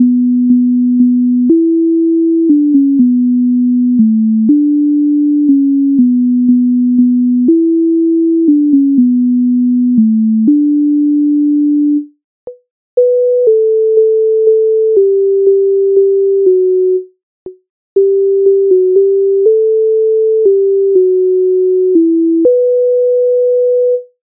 MIDI файл завантажено в тональності h-moll
Ой у вишневому садочку Українська народна пісня зі збірки Михайловської
Ukrainska_narodna_pisnia_Oj_u_vyshnevomu_sadochku.mp3